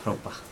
[pɹopax] noun cage